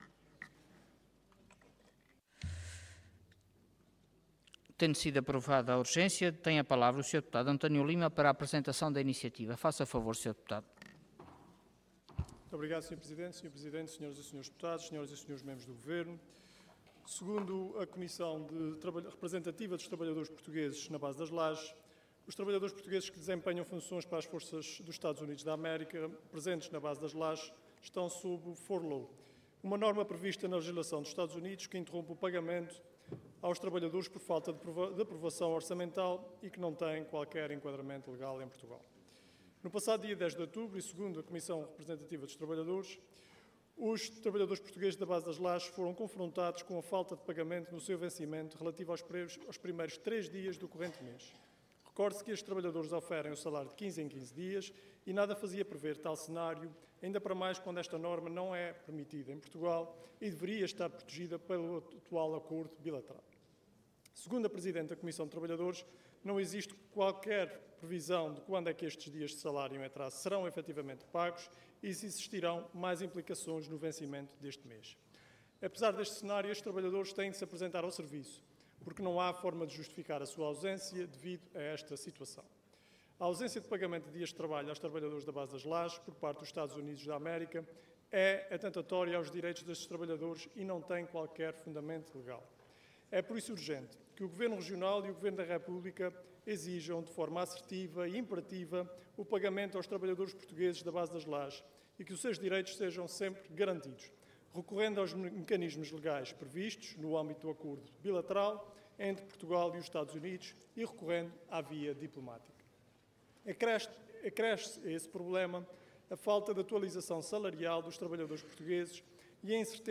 Website da Assembleia Legislativa da Região Autónoma dos Açores
Detalhe de vídeo 15 de outubro de 2025 Download áudio Download vídeo Processo XIII Legislatura Recomenda a realização de esforços diplomáticos para regularização dos salários dos trabalhadores das USFORAZORES Intervenção Projeto de Resolução Orador António Lima Cargo Deputado Entidade BE